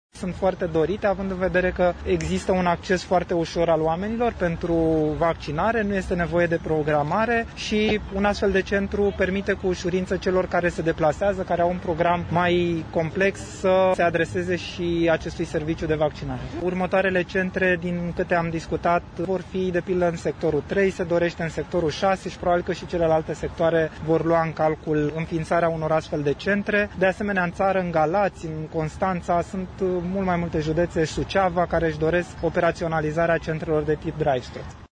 Potrivit şefului campaniei de vaccinare anti-COVID-19, Valeriu Gheorghiţă, modelul centrelor de vaccinare drive-thru urmează să fie multiplicat atât în Bucureşti, cât şi în ţară: